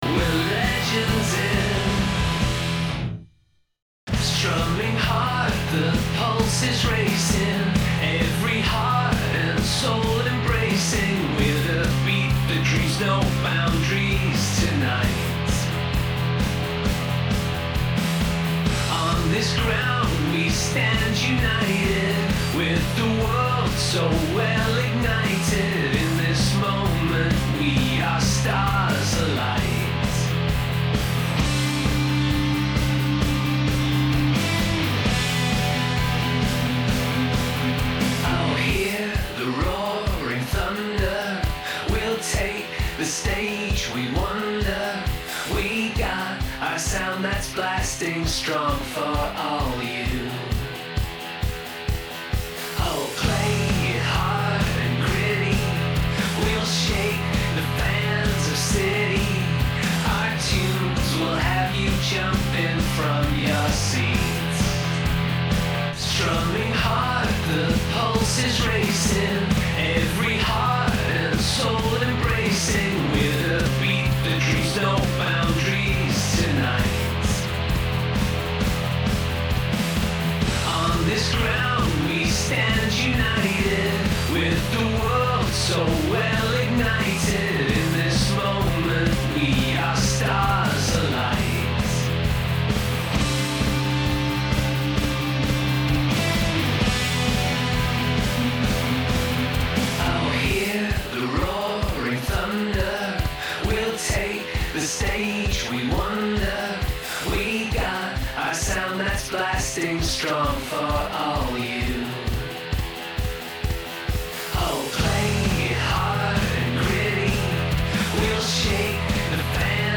Electric Bass, Drums, Electric Guitars, Chorus
Genre: Blues and Rock